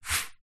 Звуки паспорта: как звучит подвинутый на стойке документ